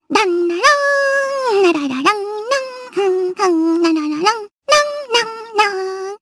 Rehartna-Vox_Hum_jp.wav